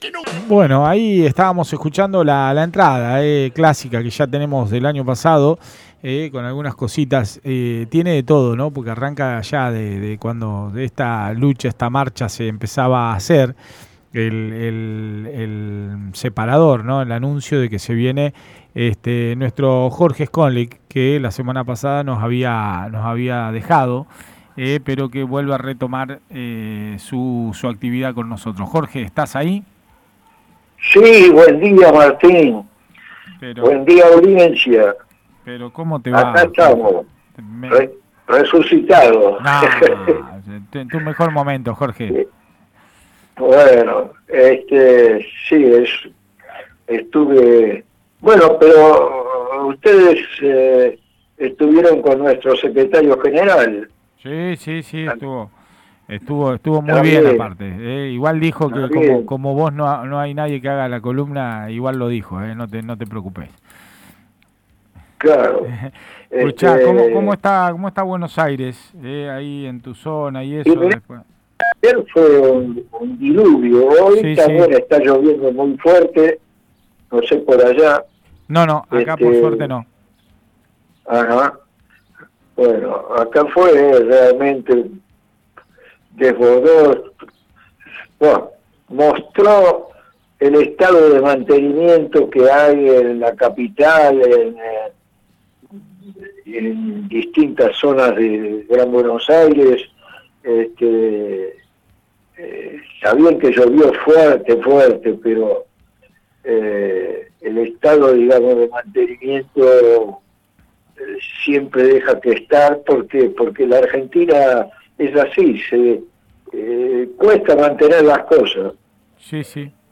En diálogo con el programa radial